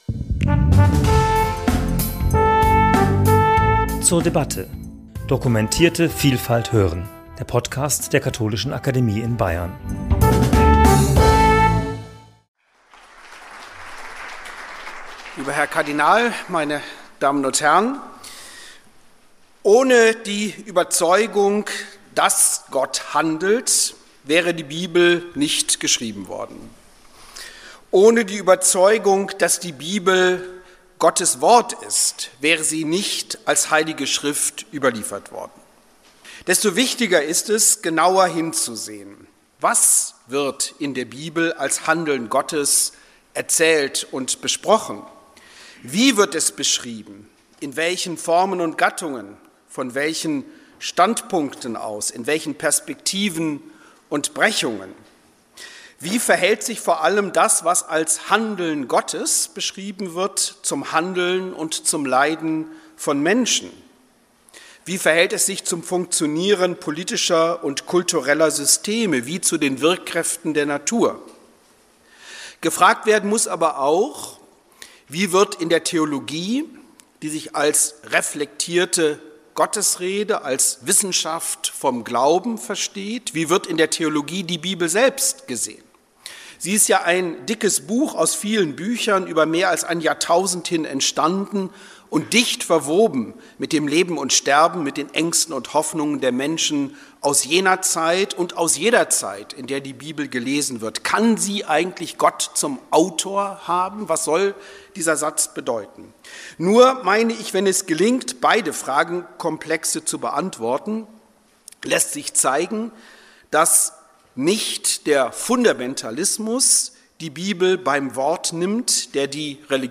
Mit der Veranstaltung „(Wie) handelt Gott?“ am 3. Dezember 2016 versuchte die Katholische Akademie Bayern den beiden im Titel verwobenen Fragen näherzukommen und Nachdenklichkeit zu erzielen.